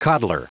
Prononciation du mot coddler en anglais (fichier audio)
Prononciation du mot : coddler